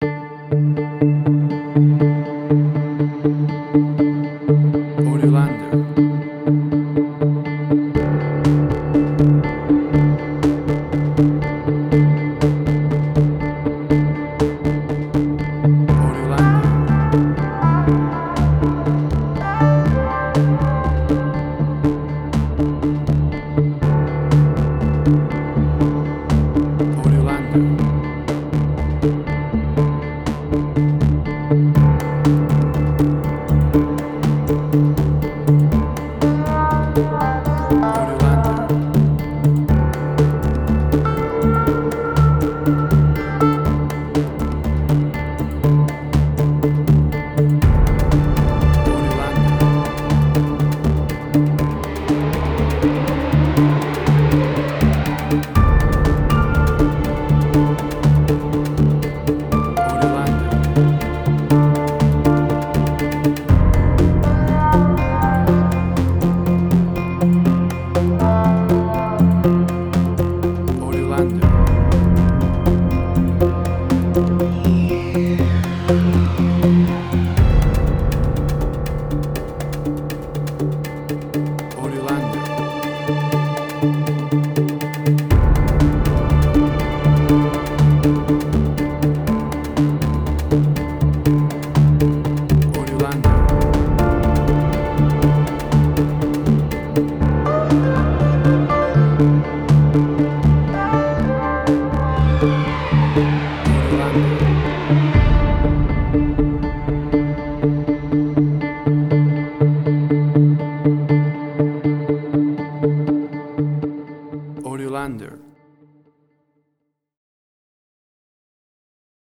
Suspense, Drama, Quirky, Emotional.
Tempo (BPM): 121